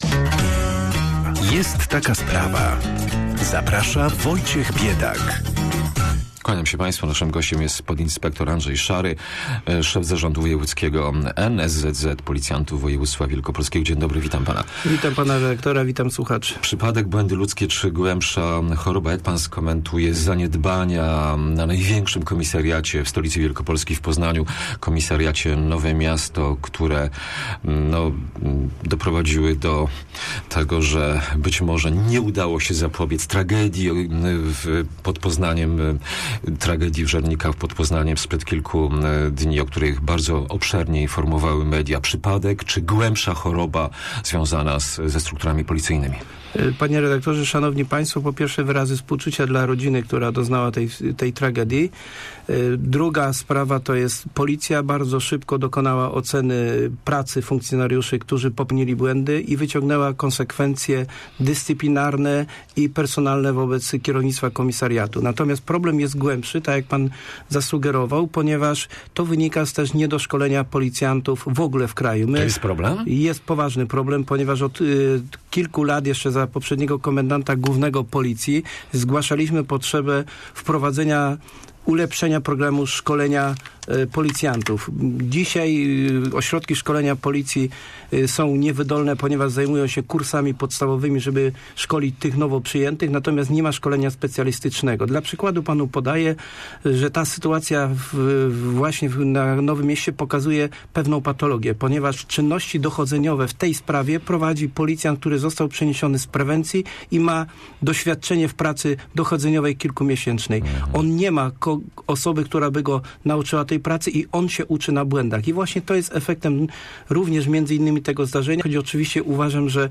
(cała rozmowa - poniżej)